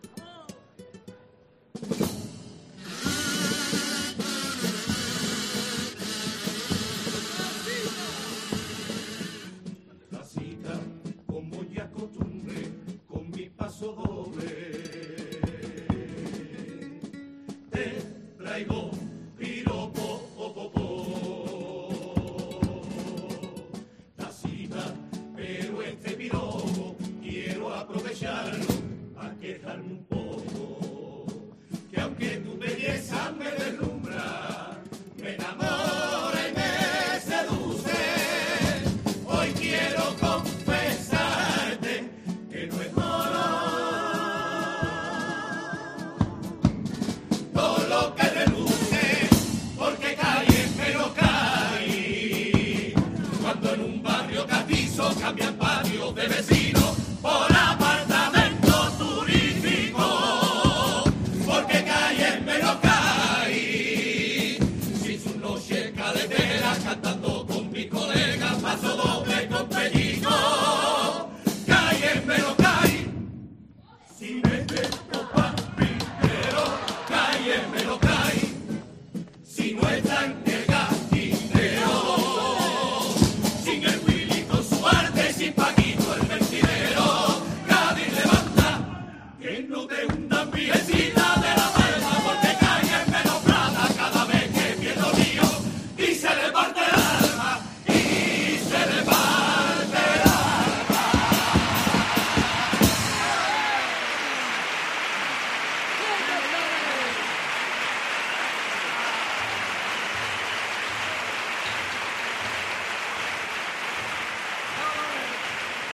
Pasodoble crítico con Cádiz de 'El Grinch de Cái'
Carnaval